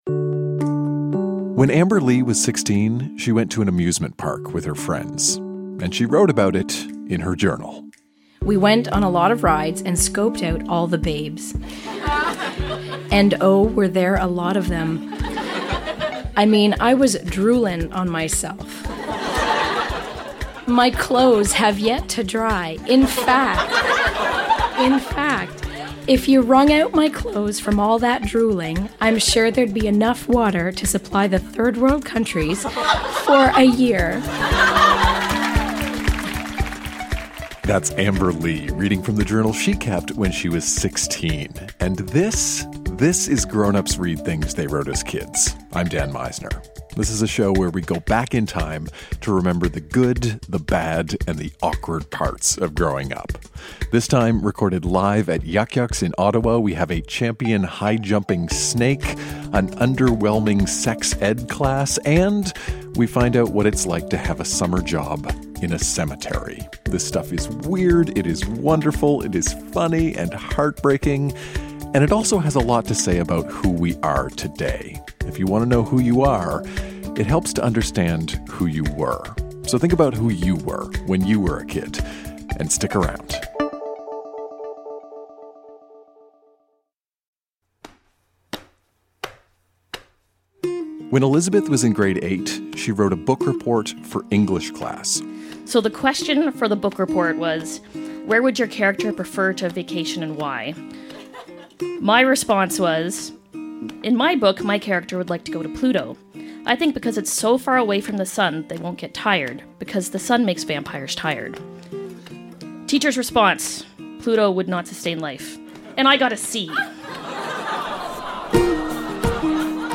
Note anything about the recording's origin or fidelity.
A champion high-jumping snake, an underwhelming sex ed class, and what it's like to work in a cemetery. Recorded live at Yuk Yuk's in Ottawa, ON.